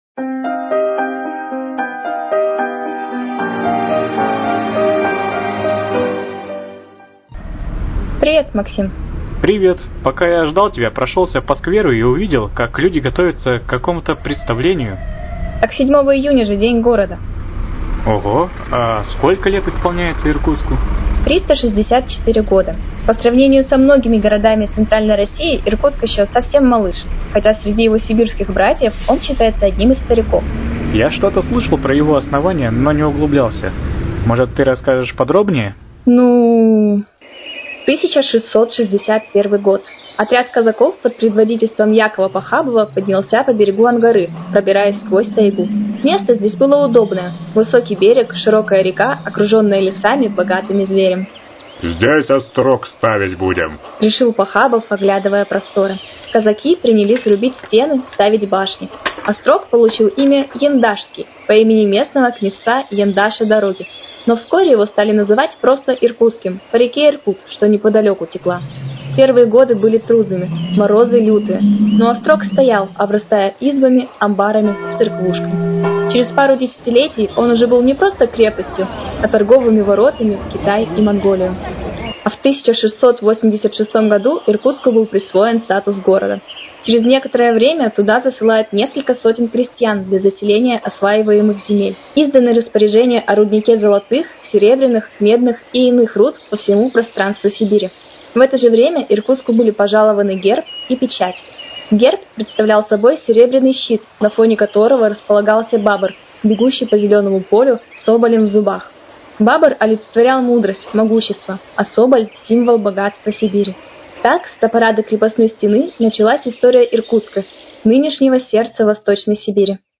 В серии «Проба пера» сегодня ко Дню города по этой теме два материала начинающих журналистов - студентов 3 курса ИРНИТУ.